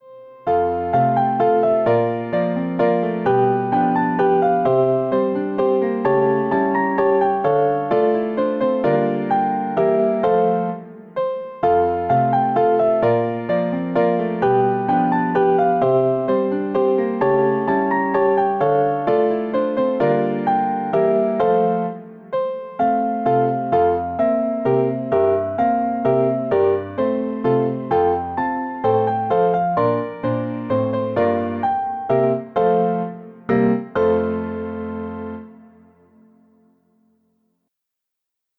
Easy / Beginner